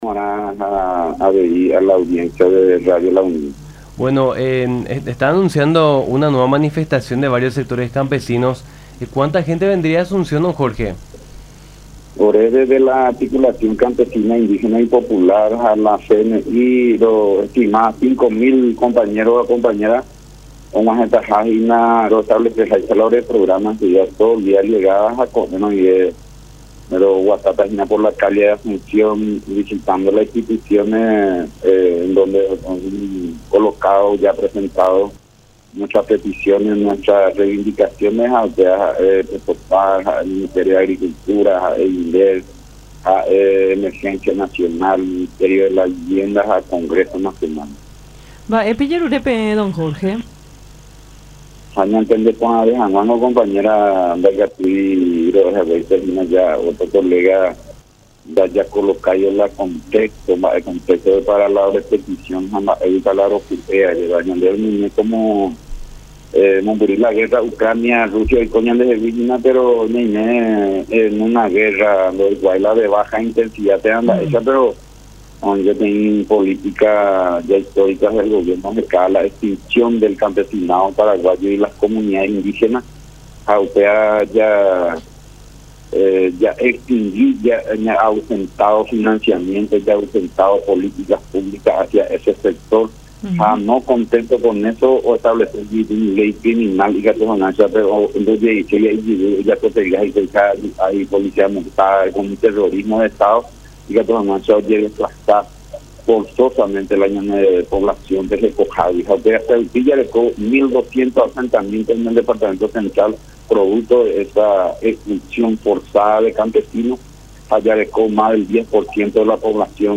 en diálogo con Nuestra Mañana por La Unión